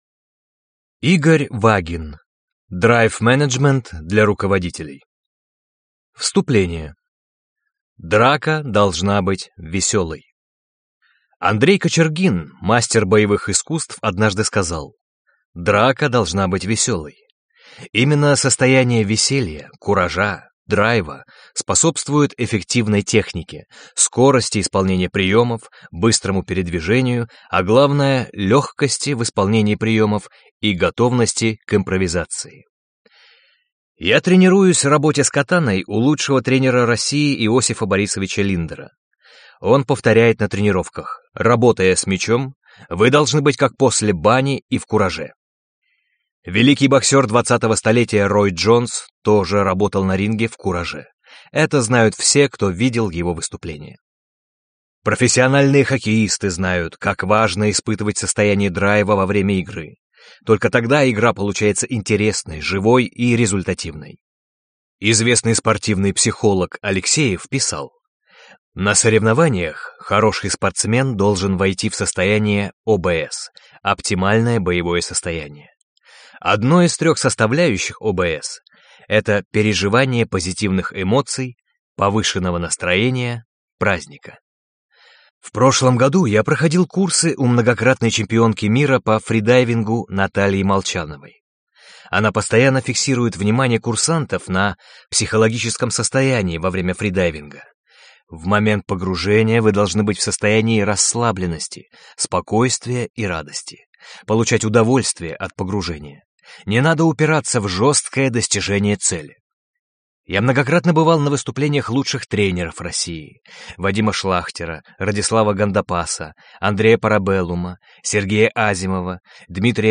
Аудиокнига Драйв-менеджмент | Библиотека аудиокниг